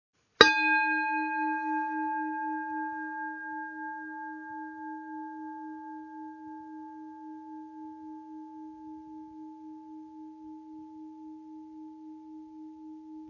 Campana Zen Daitokuji | 18 cm
Campana Zen Japonesa en bronce negro utilizada en templos Zen.
Se  un lado con utiliza golpeando un lado con el mazo y da un sonido profundo que se adentra en lo profundo de las salas de meditación silenciando la mente, serenando el espíritu y equilibrando la vibración de los que allí meditan.
Características: Aleación de cobre, zinc y silicio, conjunto con mazo y cojín brocado
daitokuji18.mp3